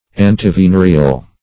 Search Result for " antivenereal" : The Collaborative International Dictionary of English v.0.48: Antivenereal \An`ti*ve*ne"re*al\, a. Good against venereal poison; antisyphilitic.